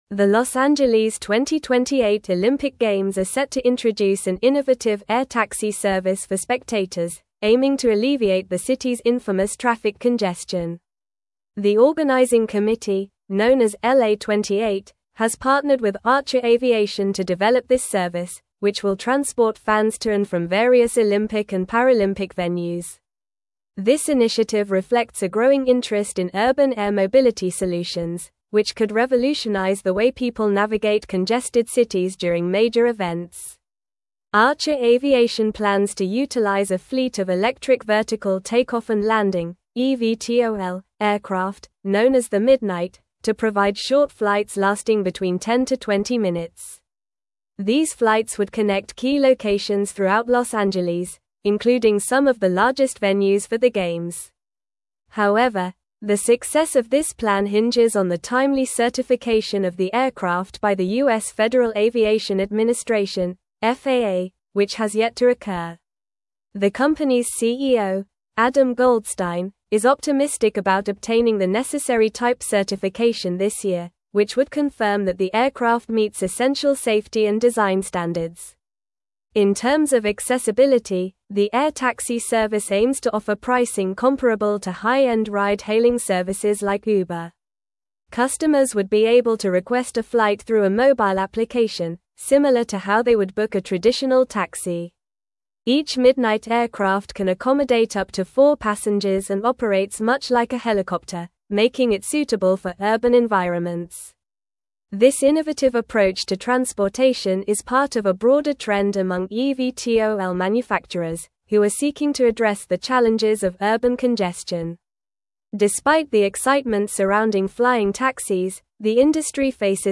Normal
English-Newsroom-Advanced-NORMAL-Reading-LA-2028-Olympics-Introduces-Innovative-Air-Taxi-Service.mp3